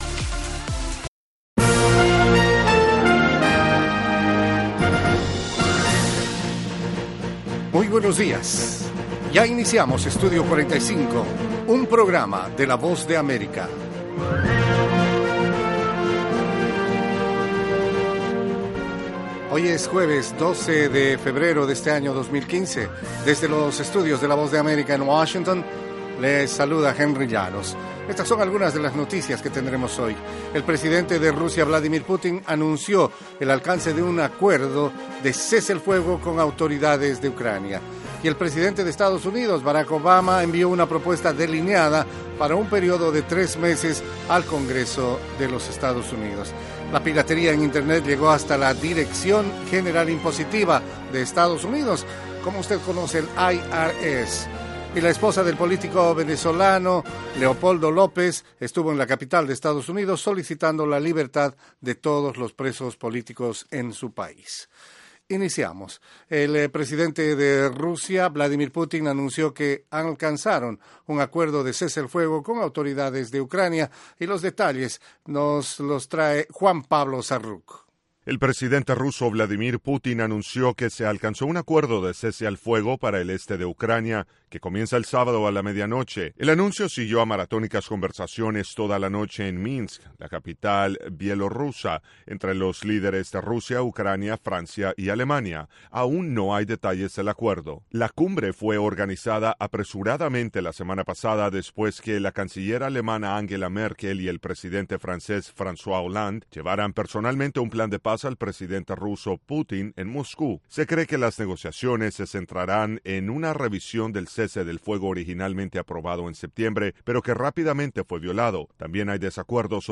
Treinta minutos de la actualidad noticiosa de Estados Unidos con análisis y entrevistas.